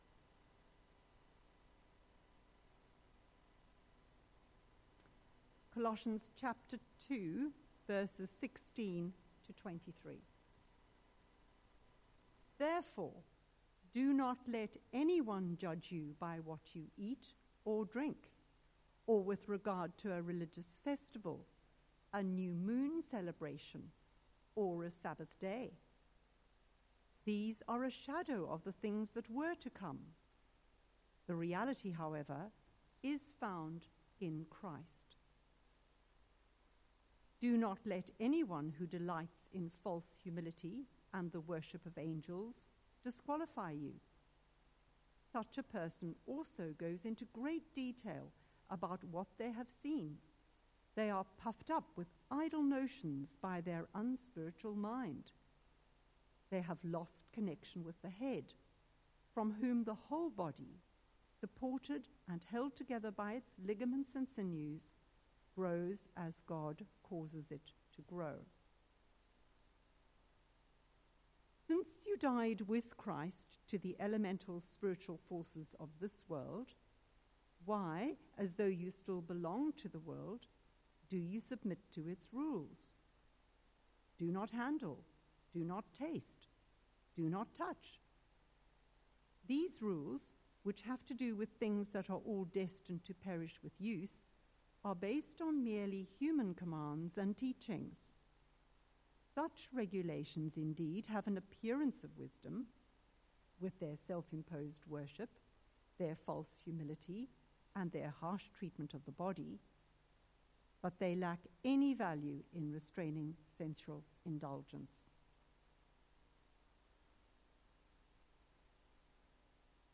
Media Library The Sunday Sermons are generally recorded each week at St Mark's Community Church.
Theme: Christ is Enough Sermon